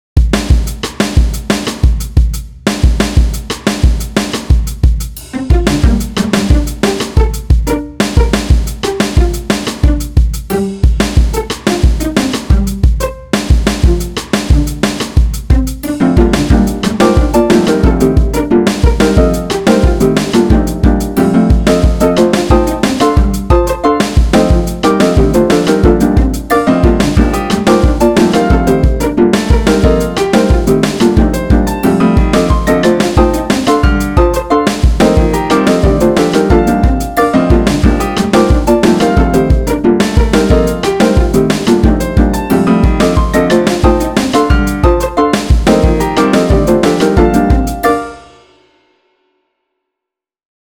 light-hearted